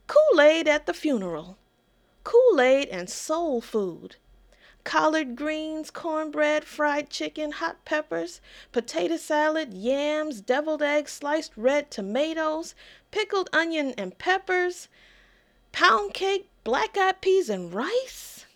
CD Audio Book (Spoken Word/Music)